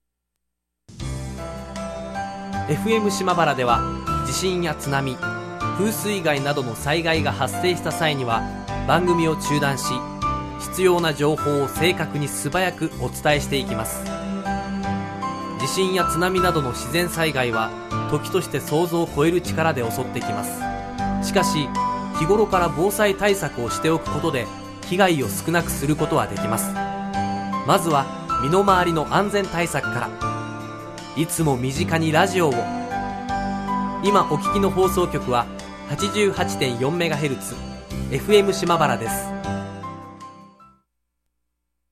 Natural Speed